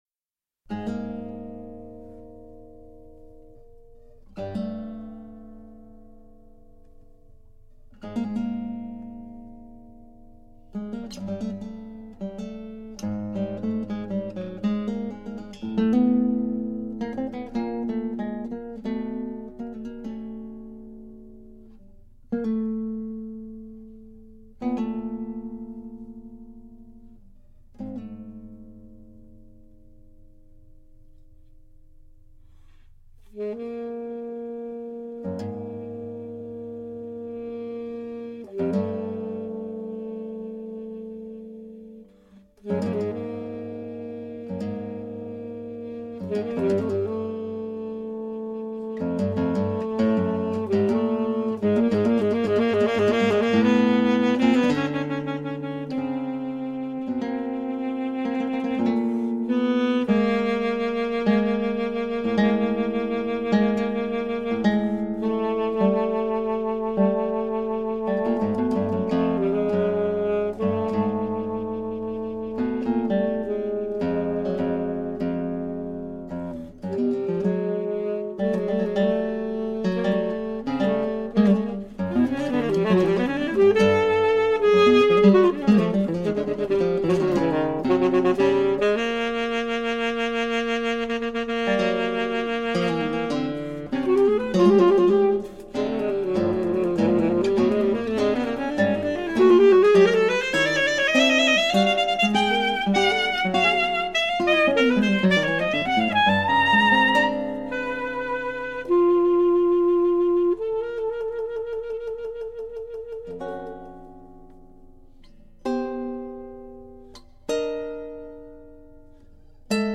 Instrumentation: alto saxophone, guitar